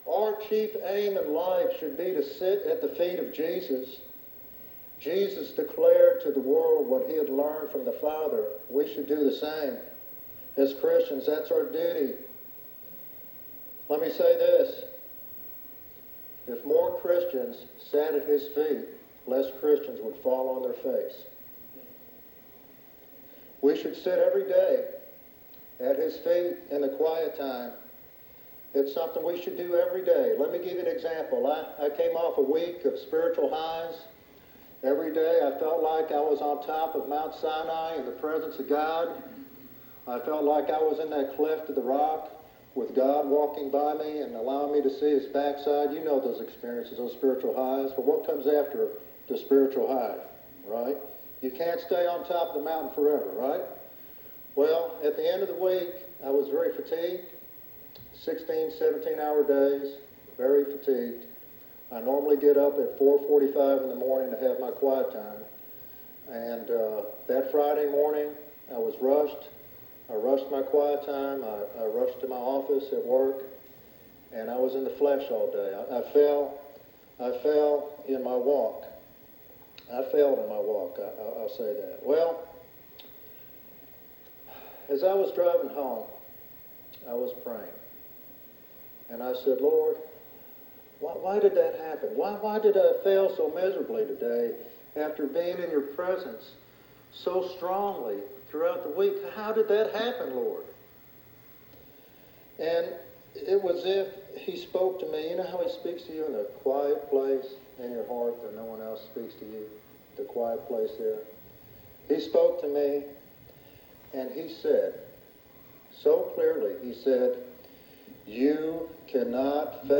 The sermon advises Christians to establish a consistent daily quiet time to meet Jesus afresh and sustain their spiritual vitality.